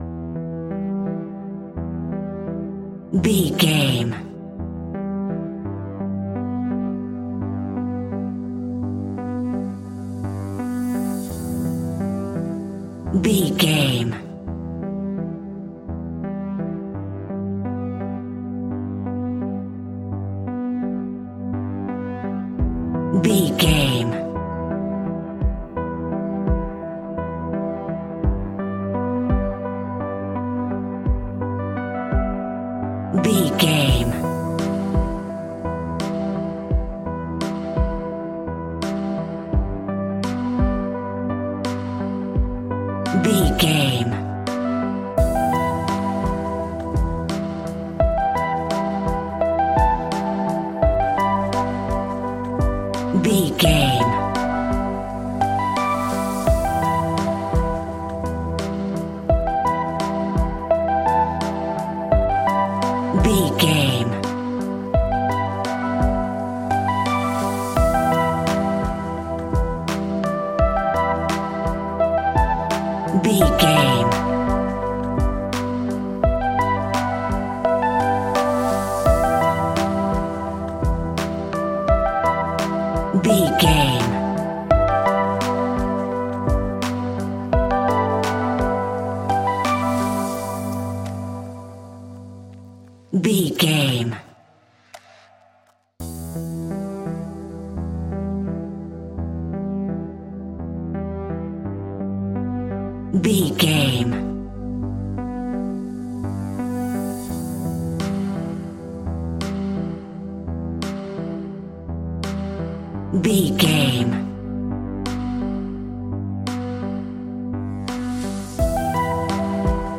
Aeolian/Minor
hip hop
hip hop instrumentals
chilled
laid back
groove
hip hop drums
hip hop synths
piano
hip hop pads